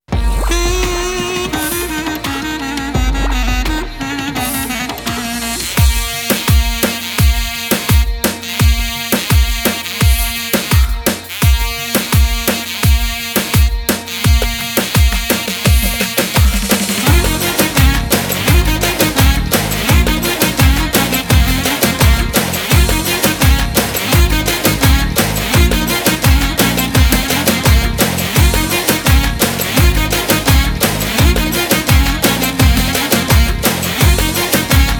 Жанр: Поп музыка / Рок
Pop, Rock